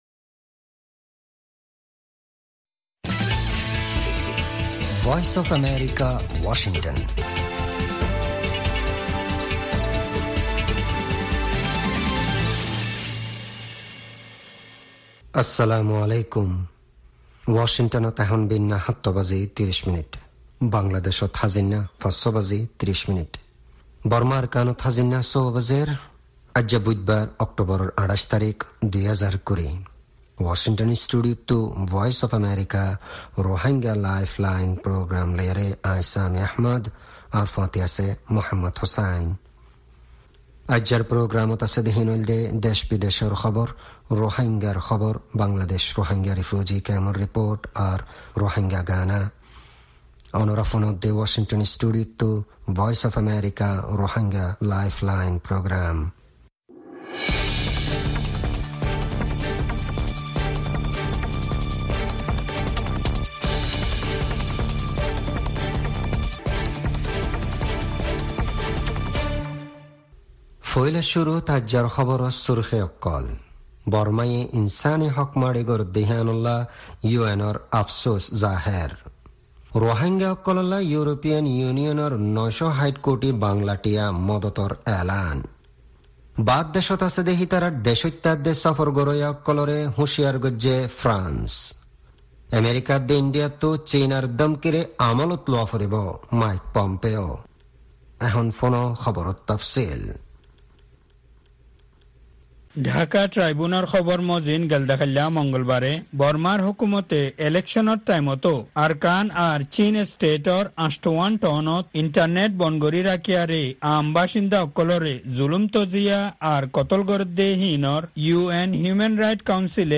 Rohingya Broadcast
News Headlines